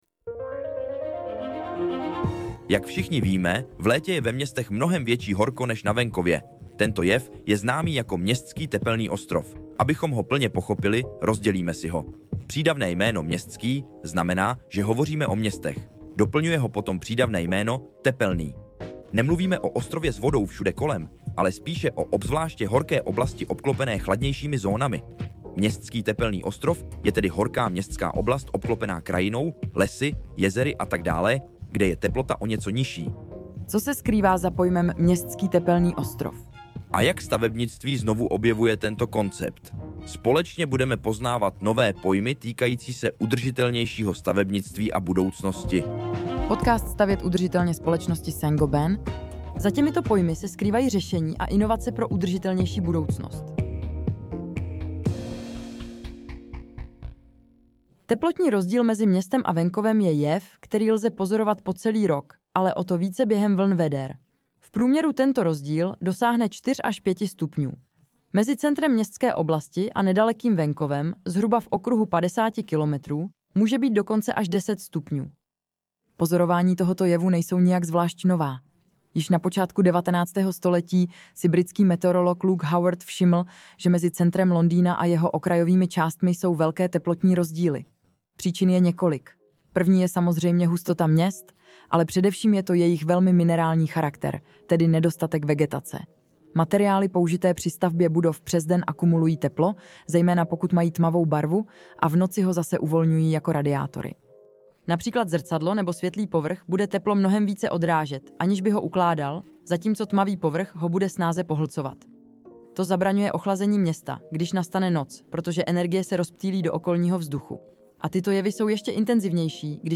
Jaký vliv má udržitelná výstavba na tepelné ostrovy a co můžeme v praxi udělat, abychom je omezili? 🌱 Tento podcast pro vás z francouzského originálu přeložila a také namluvila umělá inteligence, aby i ten byl ekologicky šetrný a pomáhal nám snižovat uhlíkovou stopu.